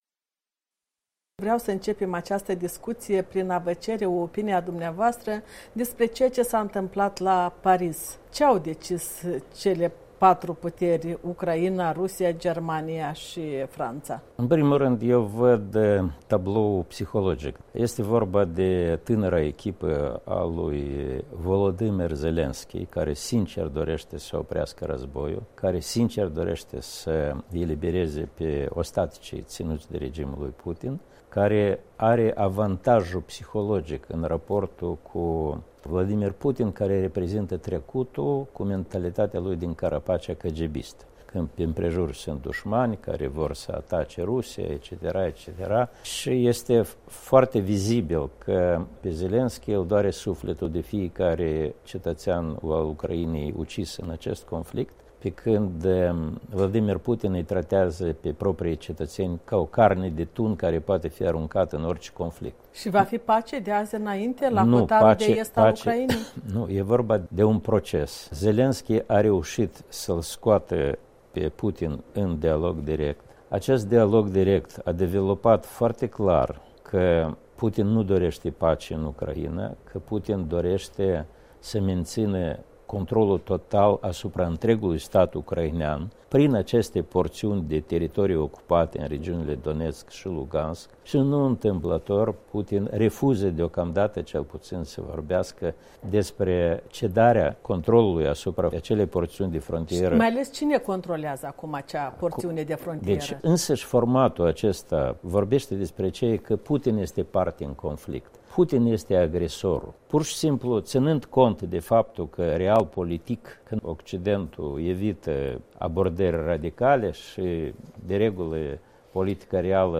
O discuție cu deputatul Blocului ACUM despre soluționarea conflictelor din Transnistria și Donbas, interesele Rusiei în regiune și reforma justiției din țară care bate pasul pe loc.